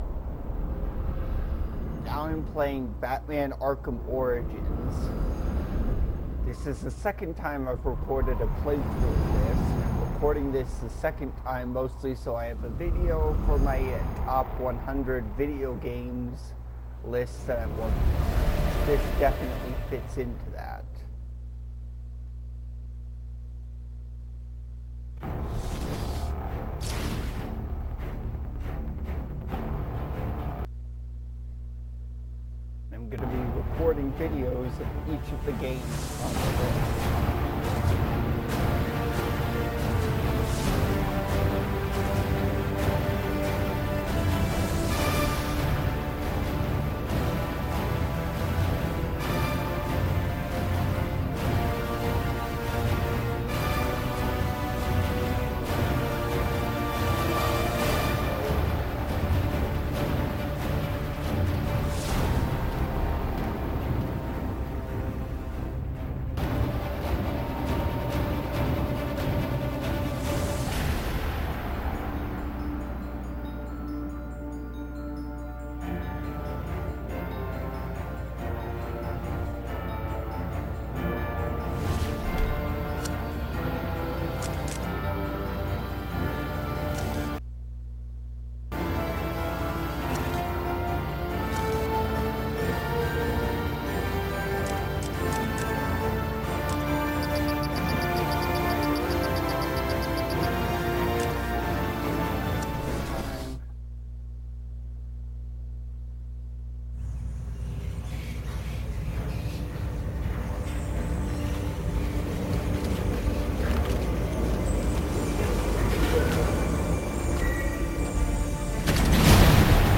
I play Batman Arkham Origins with commentary